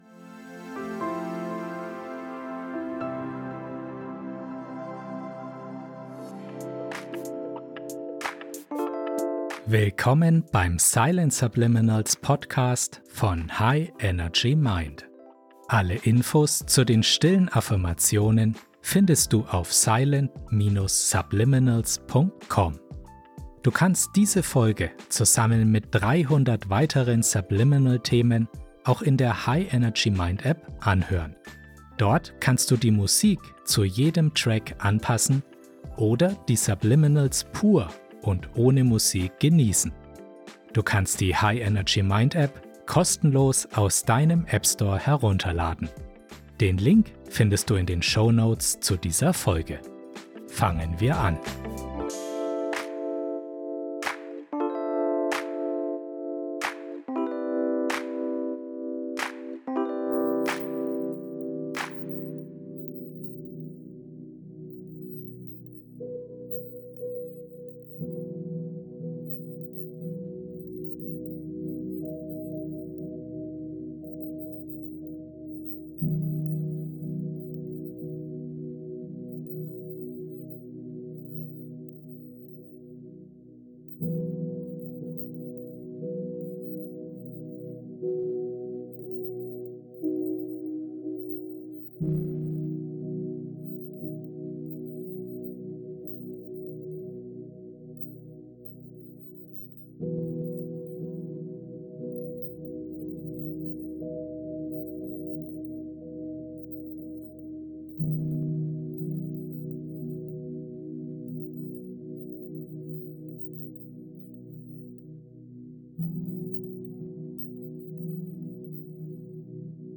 Beschreibung vor 4 Monaten Über diese Folge Entdecke das Geheimnis eines Gewinner-Mindsets – bist du bereit, dein volles Potenzial zu entfalten? In dieser Episode des Silent Subliminals Podcast begleiten dich sanfte Klänge von 432 Hz Entspannungsmusik und gezielte Silent Subliminals, um das Fundament eines erfolgreichen Geistes zu legen. Silent Subliminals sind kaum wahrnehmbare Affirmationen, die dein Unterbewusstsein erreichen und positive Veränderungen fördern.